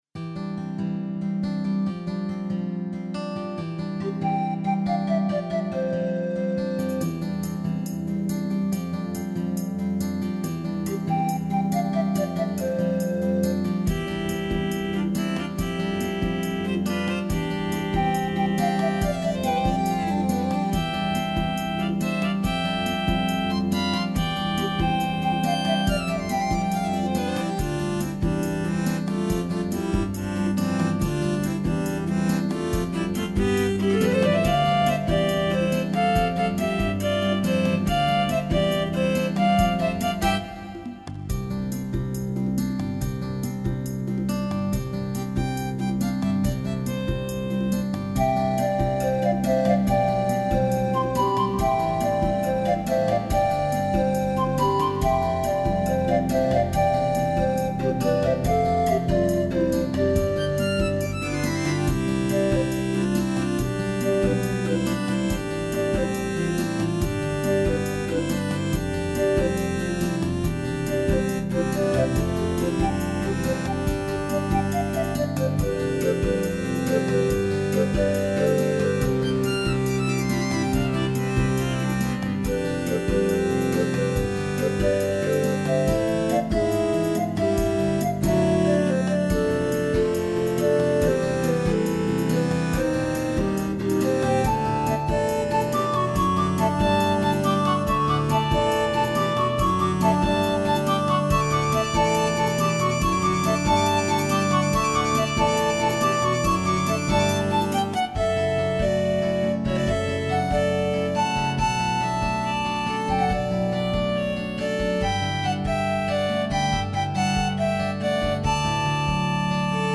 ギター曲などの素材集です。
アコースティックギターとパンフルートによる軽快な曲。